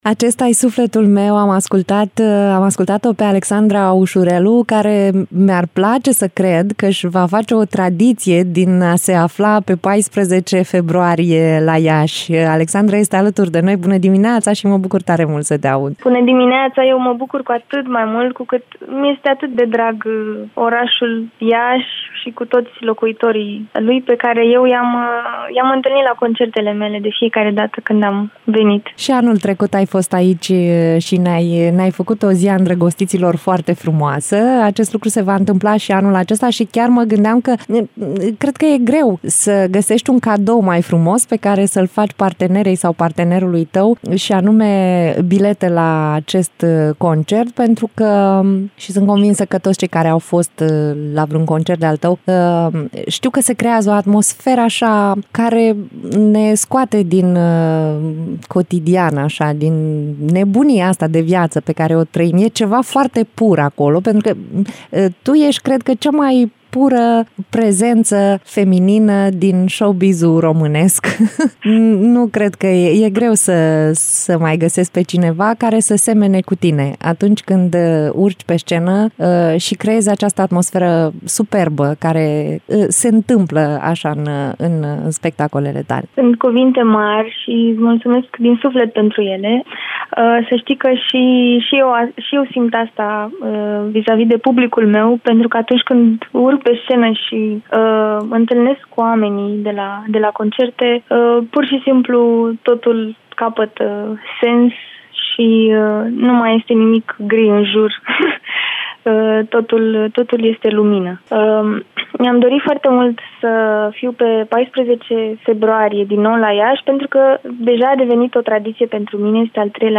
în direct la Radio Iaşi.